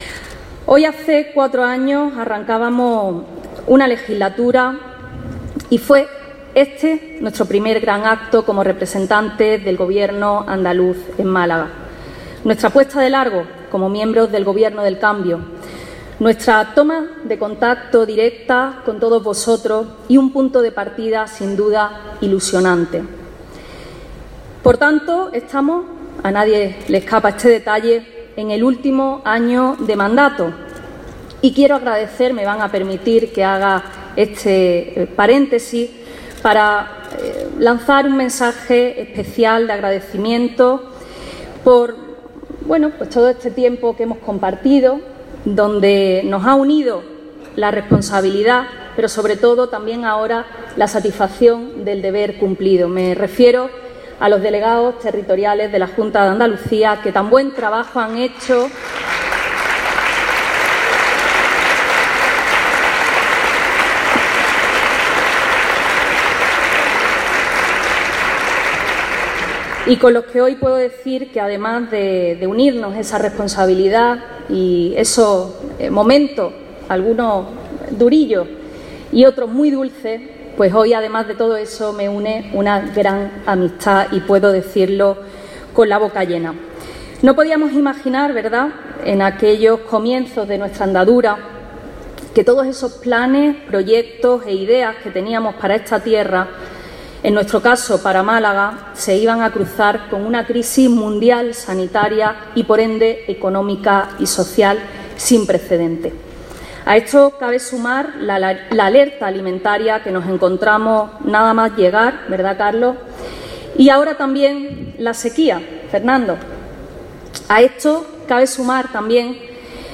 La consejera de Empleo y el consejero de Educación, junto con la delegada del Gobierno andaluz, han presidido el acto de entrega de distinciones en Málaga con ocasión del Día de Andalucía
Intervención-delegada-28f-banderas.mp3